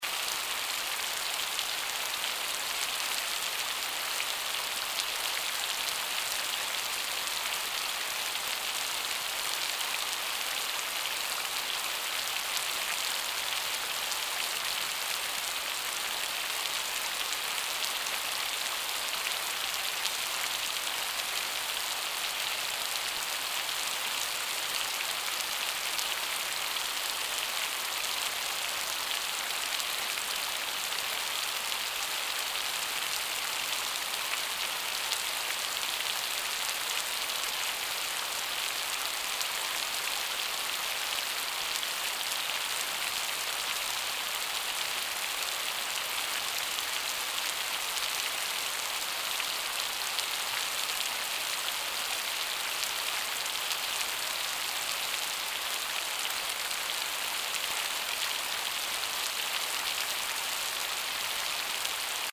ambient_rain.mp3